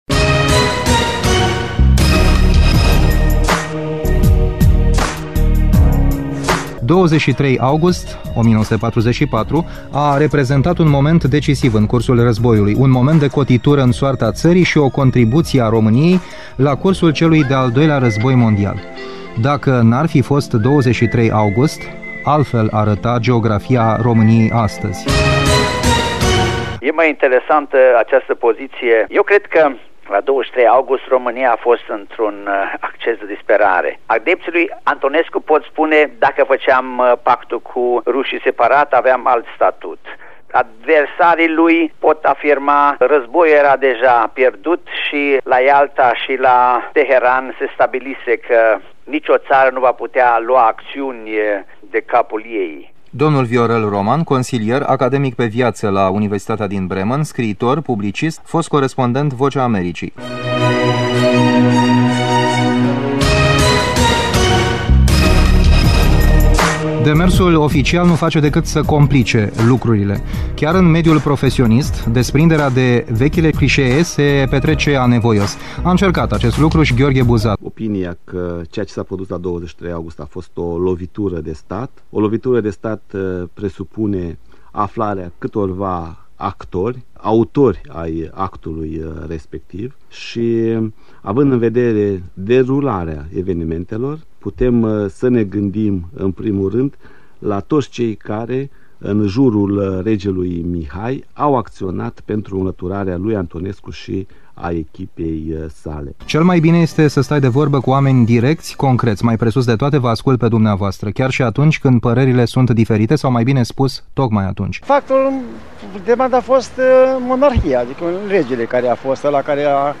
documentar
sondaj de opinie